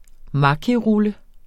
Udtale [ ˈmɑkiˌʁulə ]